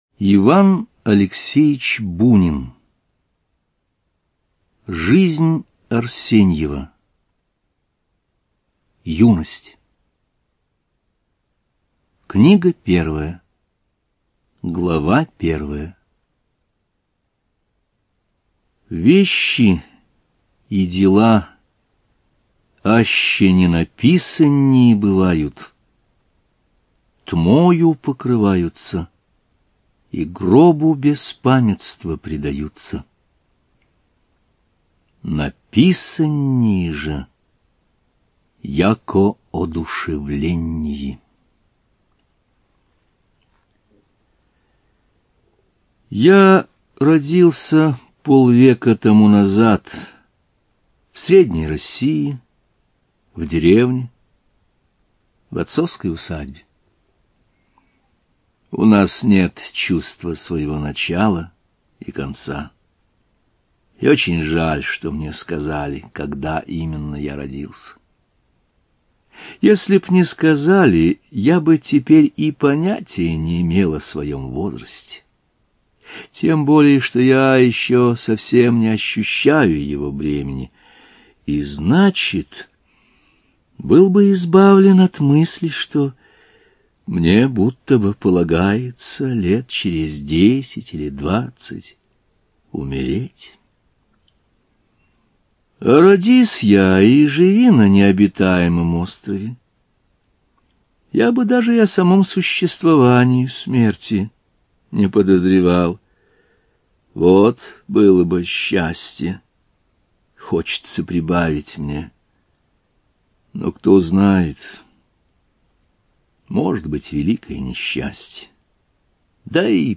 ЖанрКлассическая проза
Студия звукозаписиАрдис